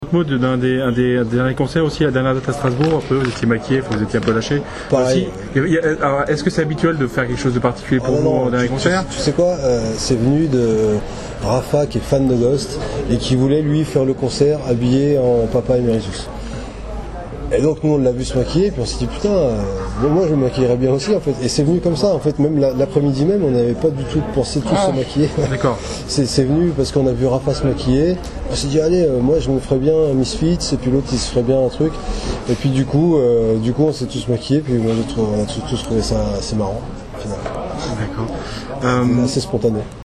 MASS HYSTERIA (Interview